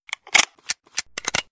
DB_reload.wav